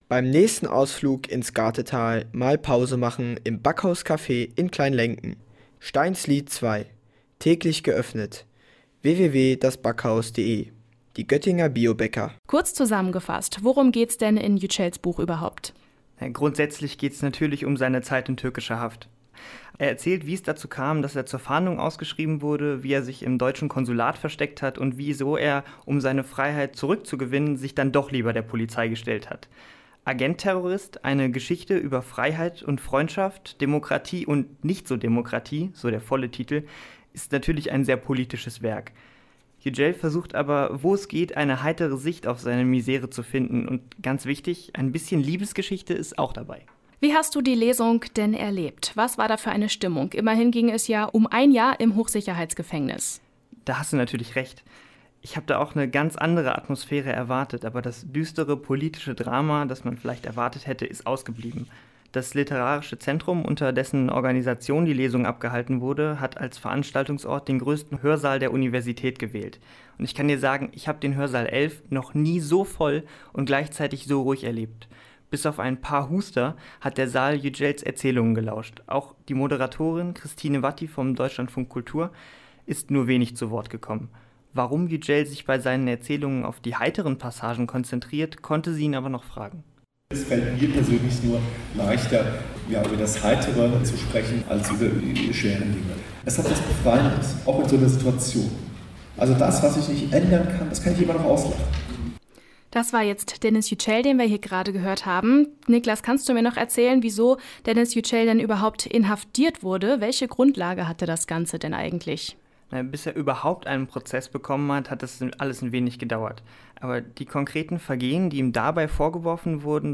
Über ein Jahr saß er im Hochsicherheitsgefängnis bis er im Februar 2018 seine Freiheit zurückgewinnt. Im Oktober ist sein Buch „Agentterrorist“ erschienen. Auf seiner Lesereise war er auch in Göttingen.